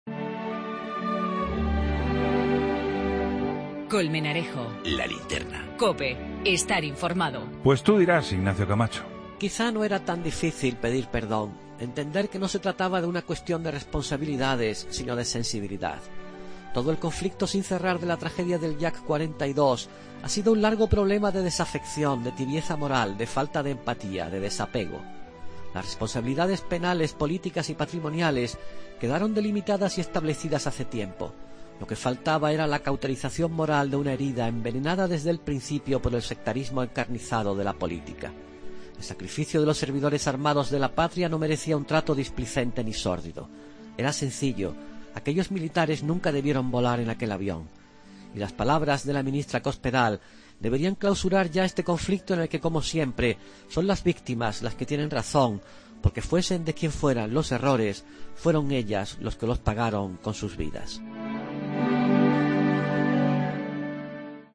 AUDIO: El comentario de Ignacio Camacho en 'La Linterna'